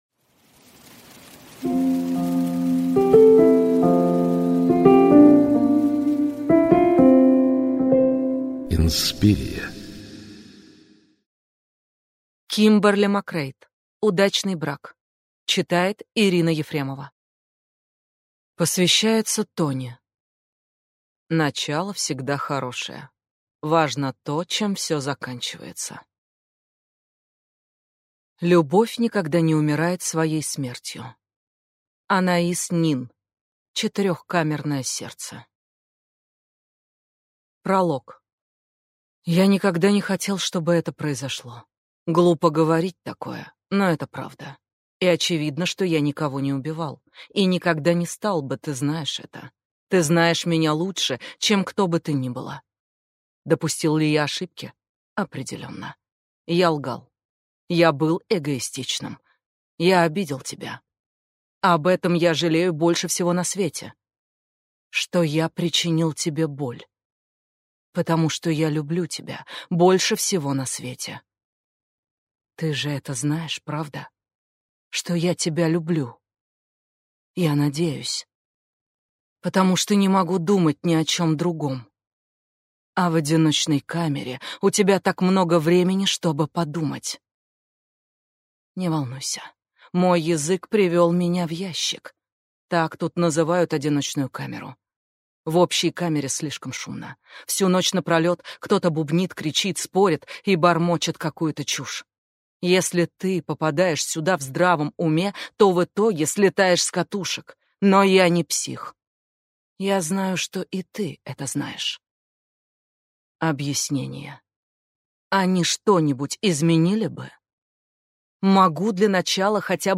Аудиокнига Удачный брак | Библиотека аудиокниг
Прослушать и бесплатно скачать фрагмент аудиокниги